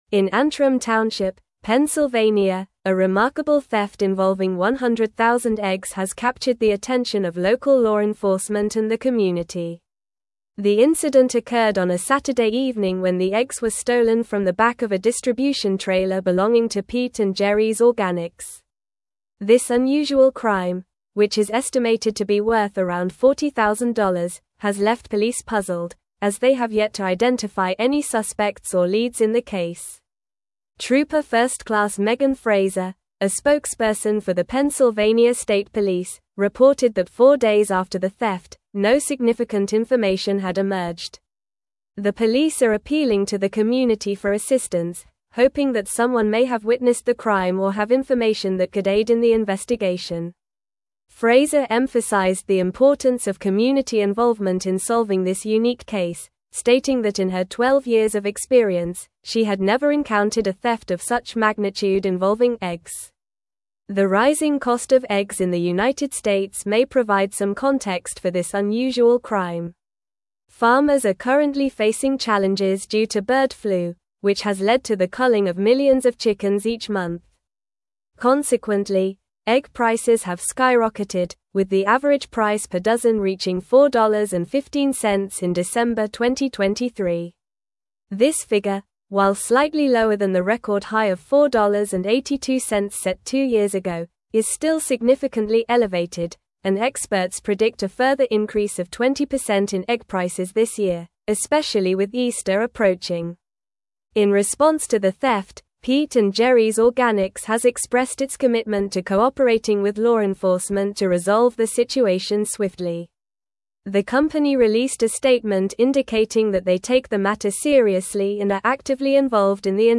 Normal
English-Newsroom-Advanced-NORMAL-Reading-Massive-Egg-Theft-Leaves-Pennsylvania-Authorities-Searching-for-Clues.mp3